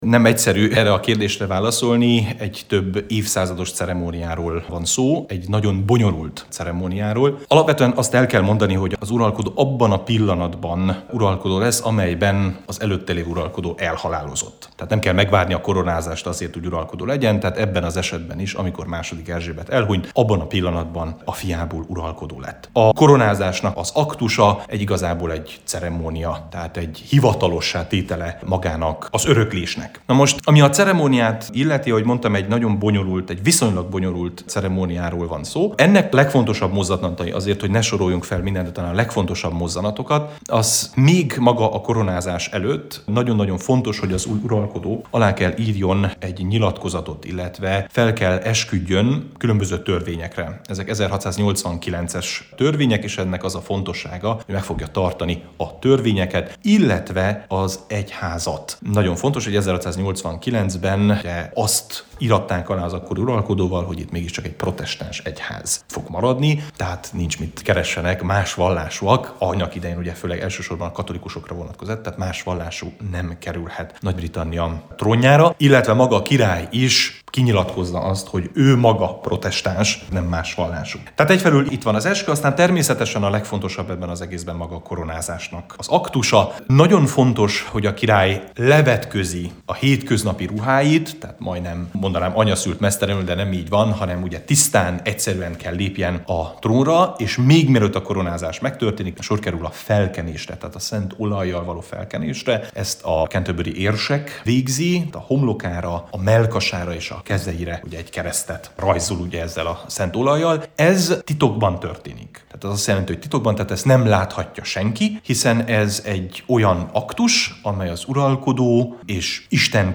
történész, történelemtanár